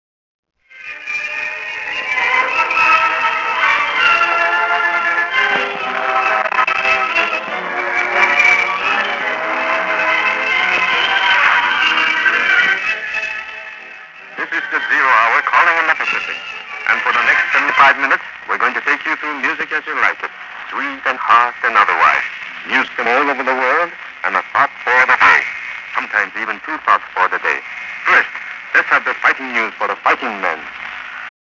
This is the best known of her broadcasts, having less reception interference and more availability than any other known recordings of "The Zero Hour".
Listening to 'The Zero Hour' The beginning of "The Zero Hour" on August 14, 1944.